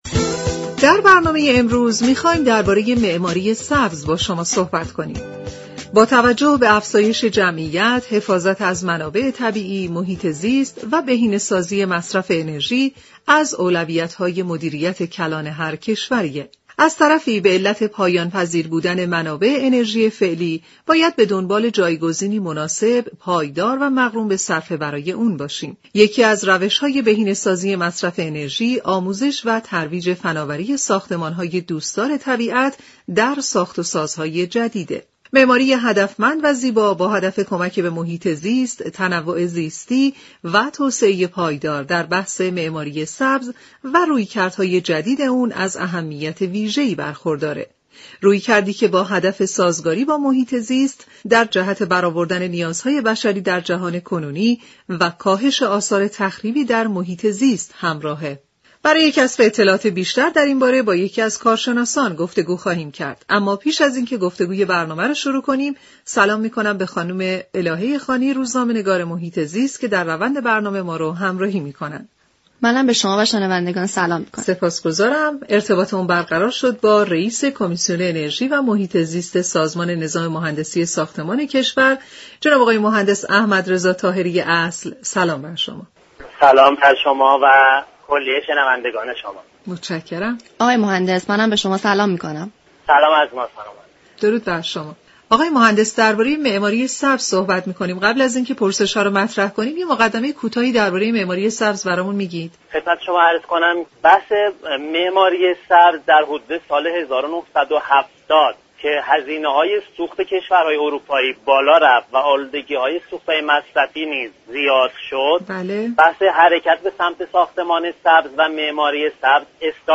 در گفت و گو با برنامه «سیاره آبی» درباره معماری سبز